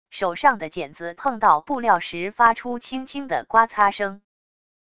手上的茧子碰到布料时发出轻轻的刮擦声wav音频